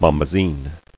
[bom·ba·zine]